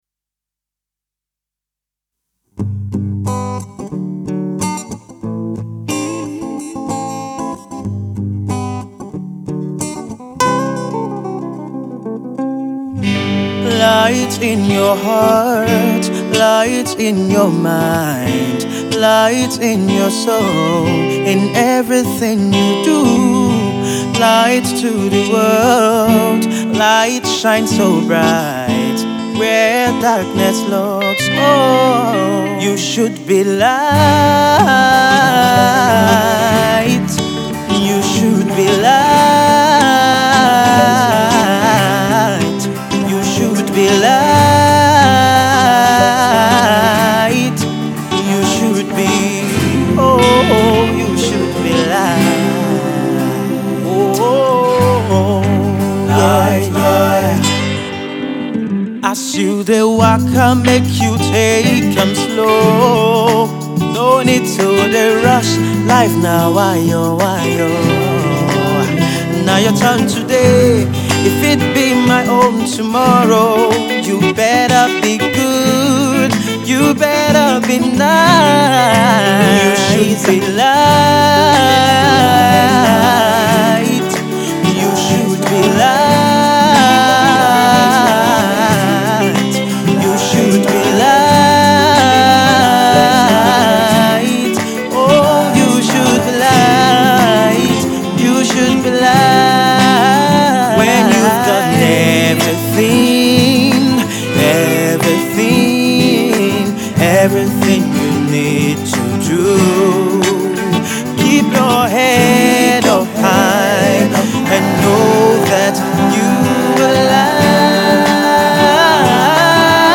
soulful inspiring tune